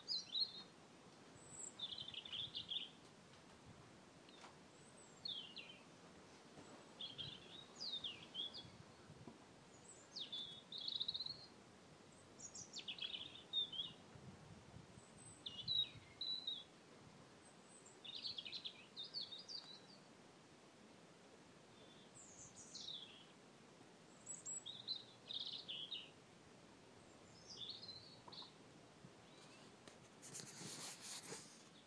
tweeting bird boo